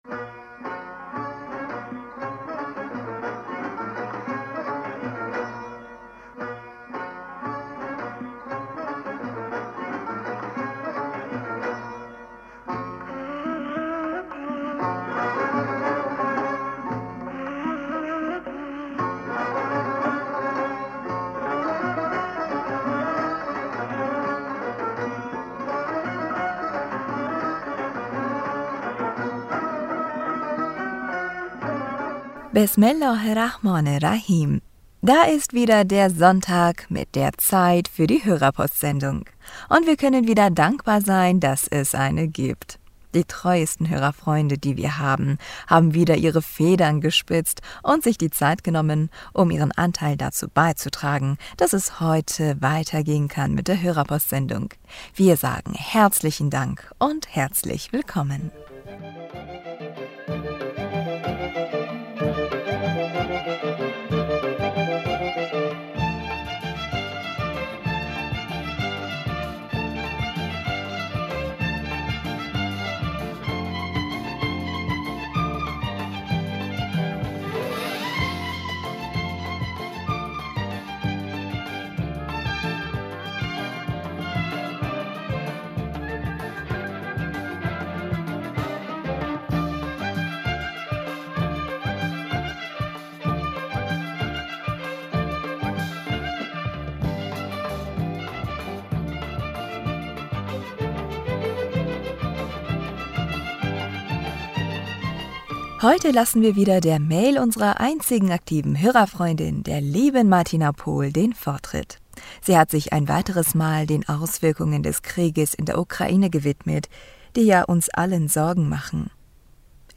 Hörerpostsendung am 21. August 2022 Bismillaher rahmaner rahim - Da ist er wieder der Sonntag mit der Zeit für die Hörerpostsendung.